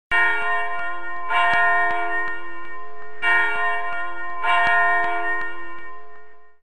Church Bells